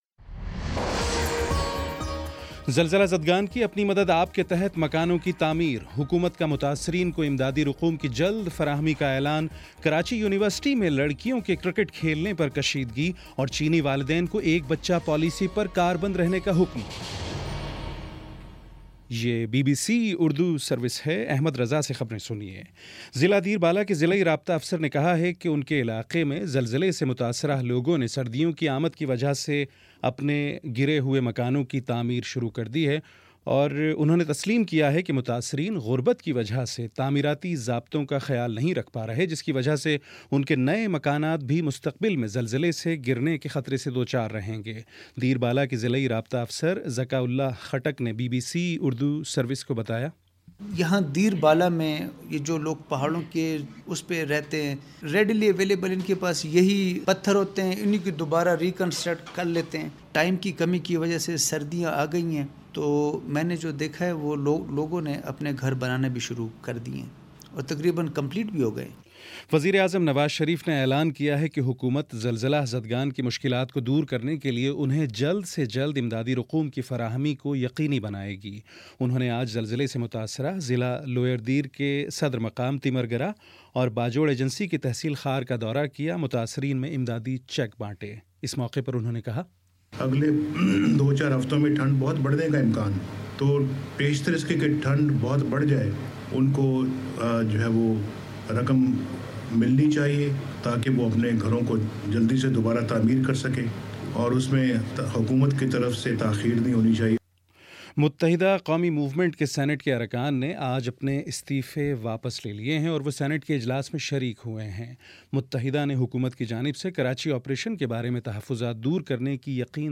نومبر 02 : شام پانچ بجے کا نیوز بُلیٹن